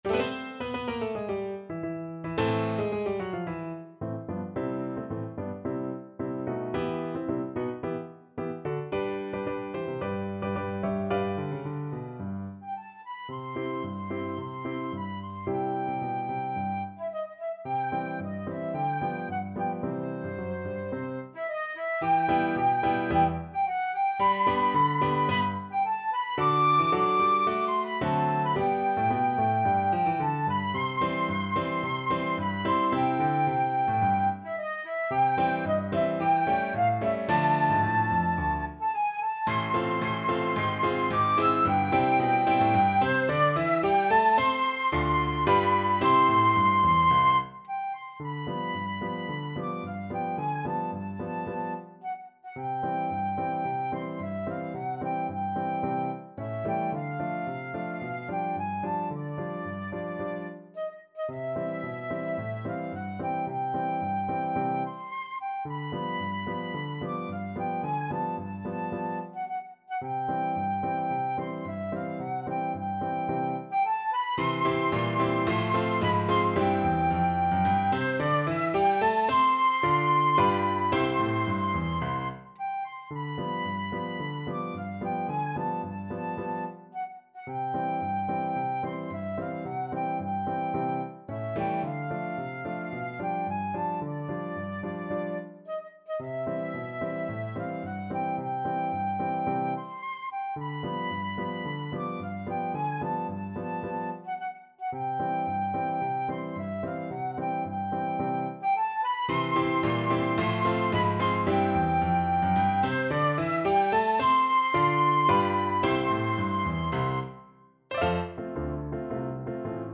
Flute
2/2 (View more 2/2 Music)
C major (Sounding Pitch) (View more C major Music for Flute )
Classical (View more Classical Flute Music)